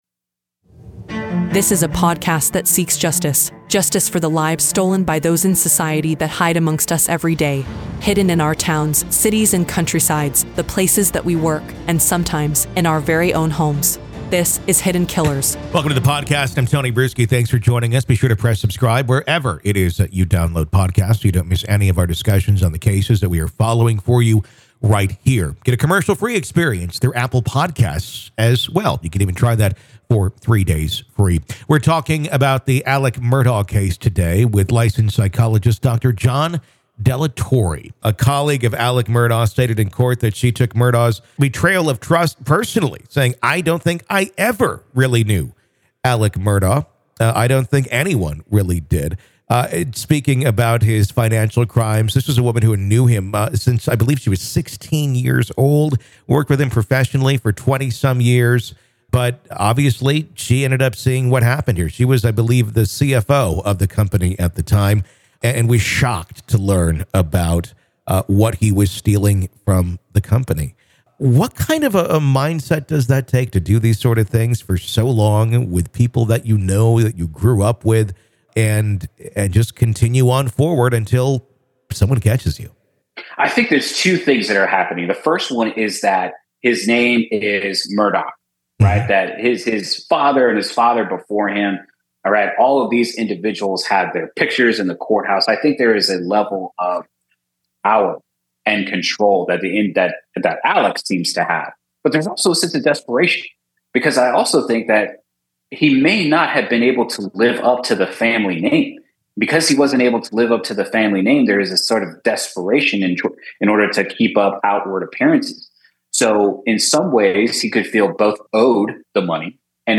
Today we’re talking with Licensed Psychologist